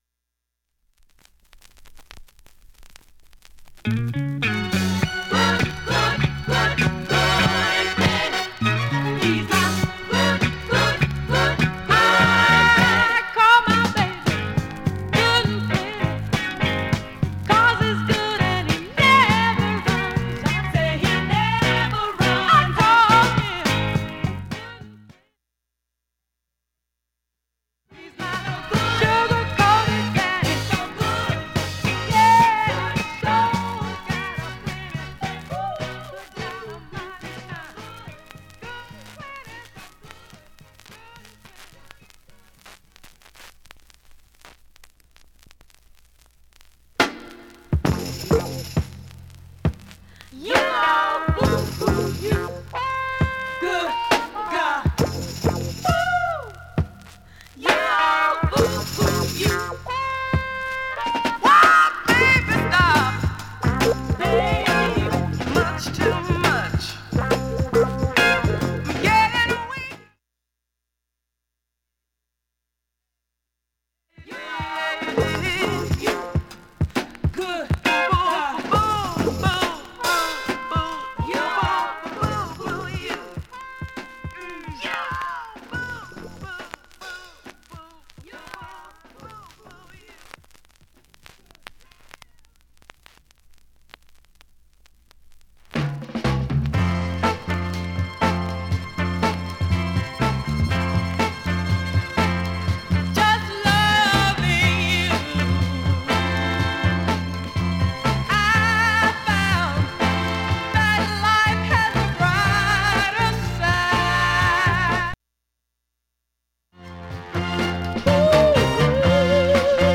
聴感上聴き取りにくいレベルです。
B面無音部ダイジェスト試聴入れてます、
５回までのかすかなプツが３箇所
単発のかすかなプツが８箇所